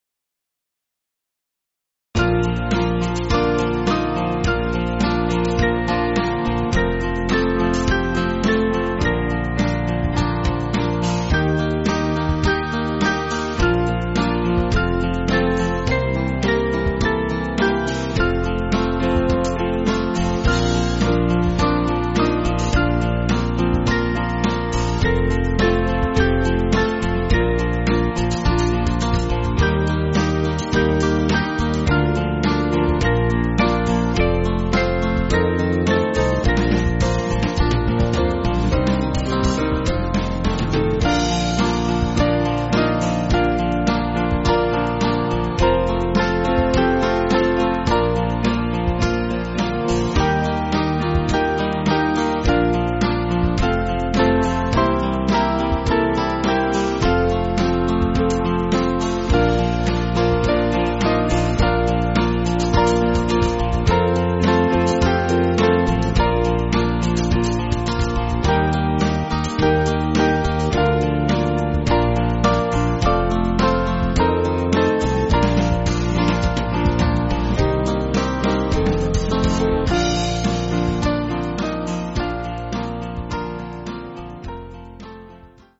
Small Band
(CM)   8/Bb